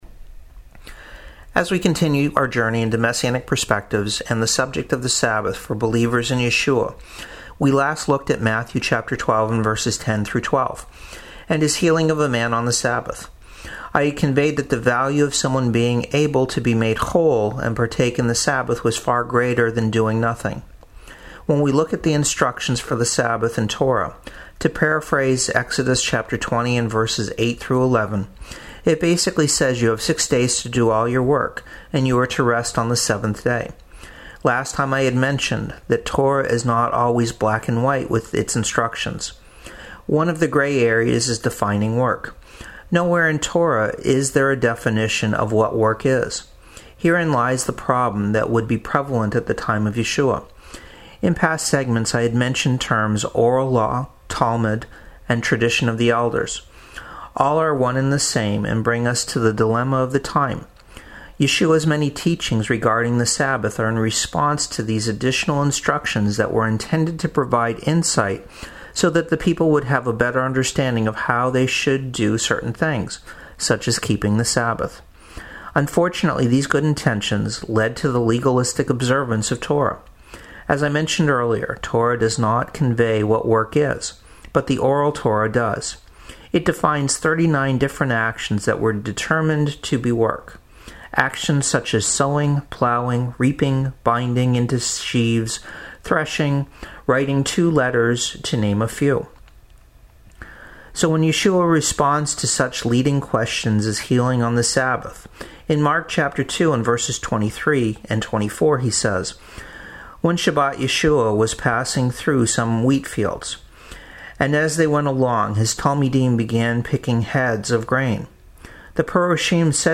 Messianic Perspectives is a radio segment that aired weekdays on radio stations WRKJ 88.5 and WMTP 91.1 in Maine.